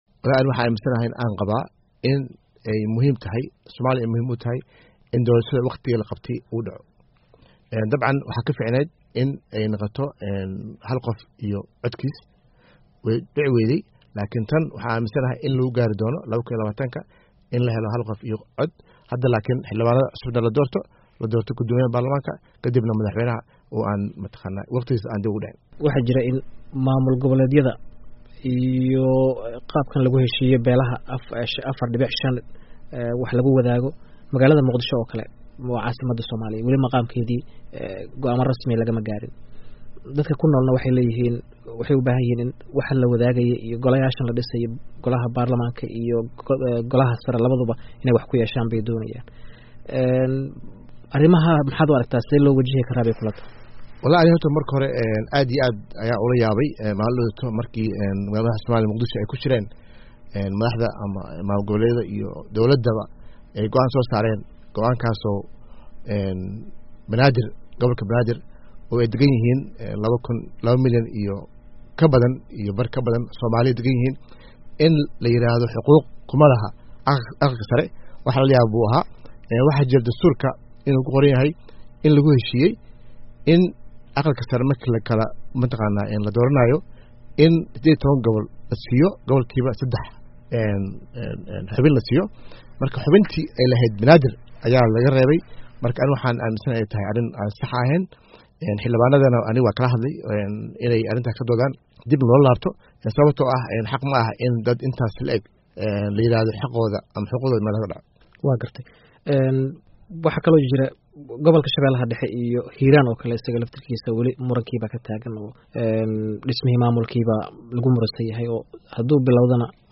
Wareysi